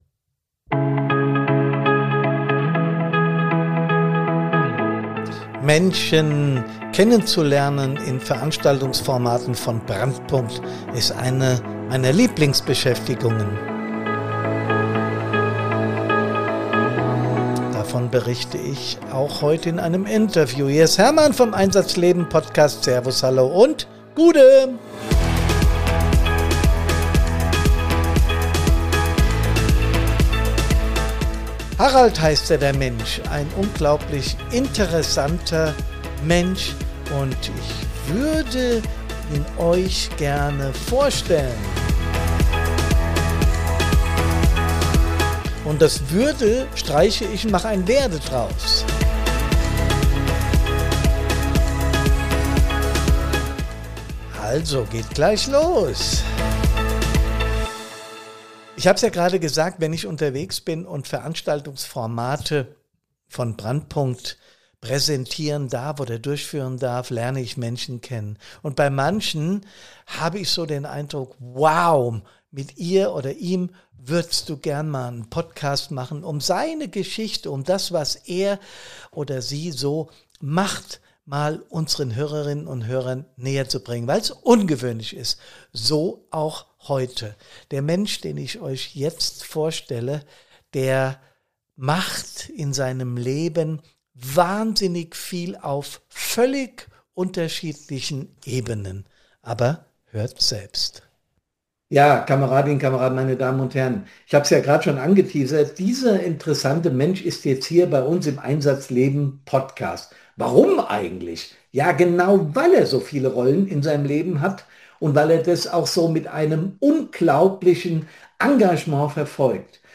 Wir sprechen darüber, woran man merkt, dass es zu viel wird, wie eine gesunde innere Haltung trotz Druck und Stress entsteht, warum Humor mehr ist als ein netter Charakterzug und was innere Stabilität im Alltag wirklich bedeutet. Ein persönliches Gespräch mit vielen Anknüpfungspunkten für alle, die selbst ordentlich viel Verantwortung tragen, für Feuerwehrangehörige, Führungskräfte, Ehrenamtliche und alle, die wissen, wie schnell zwischen Pflichtgefühl und Überforderung nur ein schmaler Grat liegt.